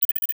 Holographic UI Sounds 78.wav